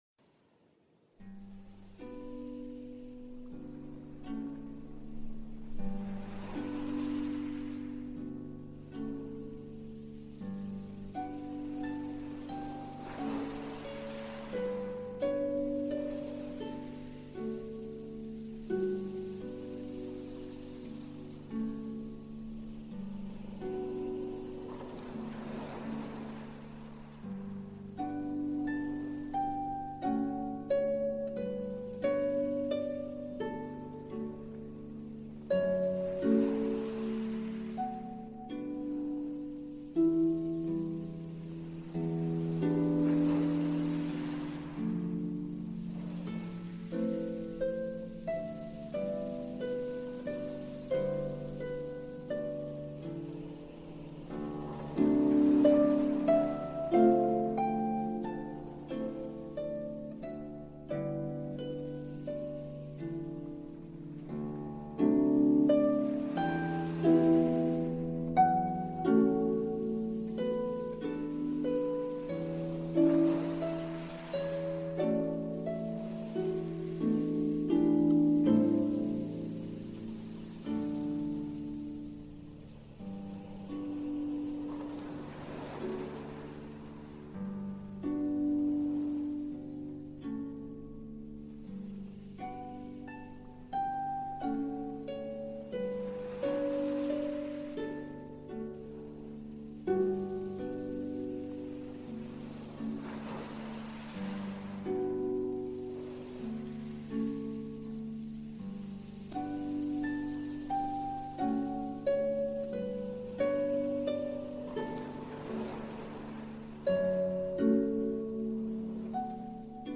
Manhattan Calme : Ambiance Focus